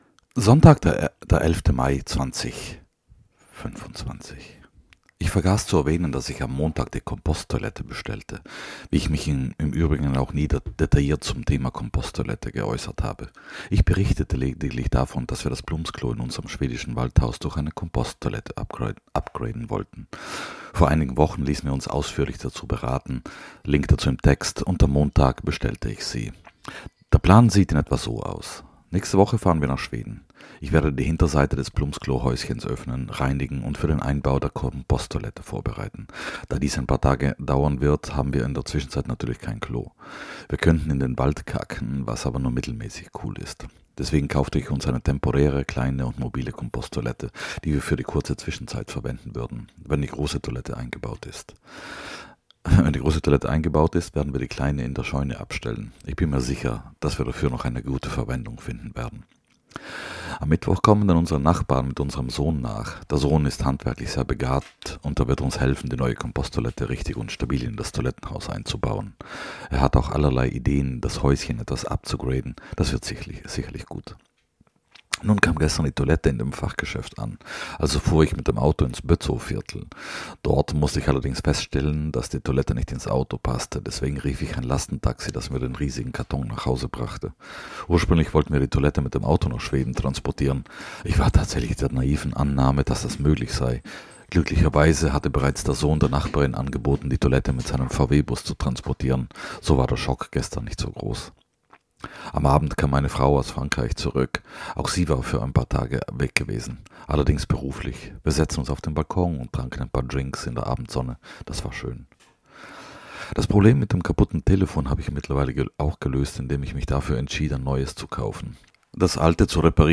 [So, 11.5.2025 - Komposttoilette, Pixel 8a] - es regnet